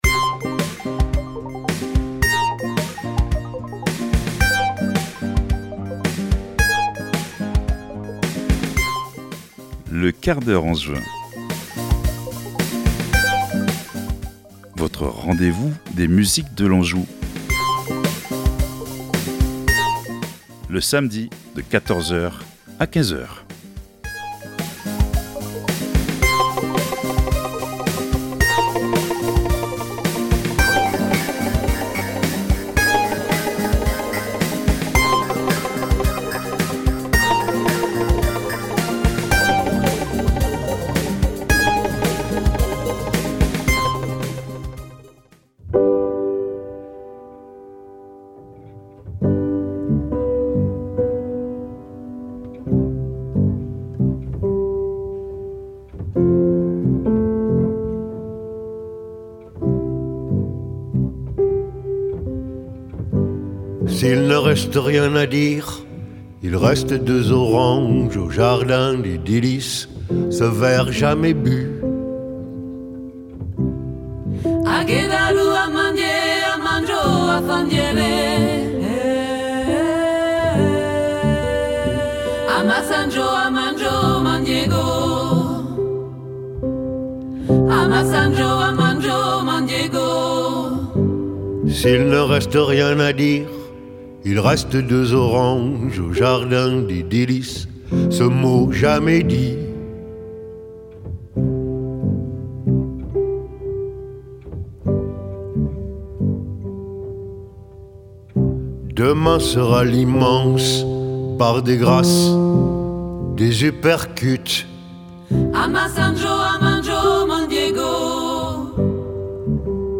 Pendant une heure, nous vous offrons un voyage sensible qui va du rock à la chanson française, en passant par le rap, le jazz, le blues ou les musiques du m